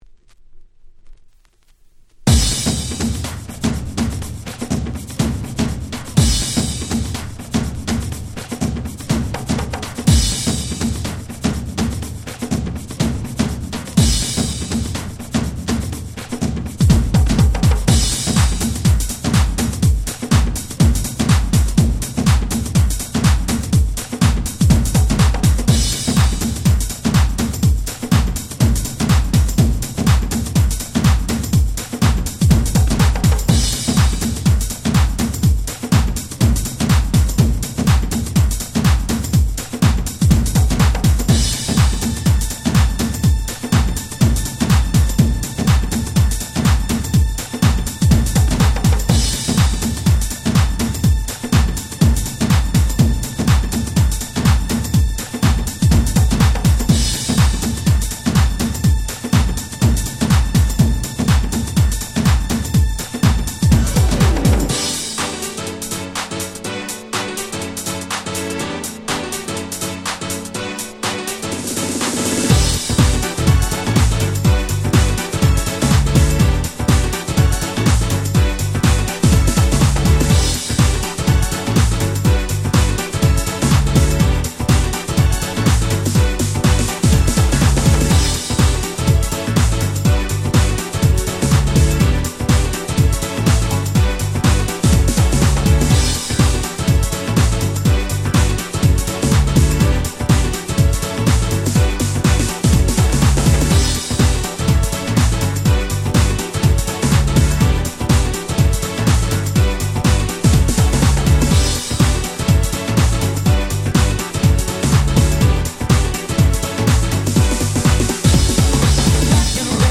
97' Very Nice Vocal House !!
込み上げるVocalが気持ち良すぎます！！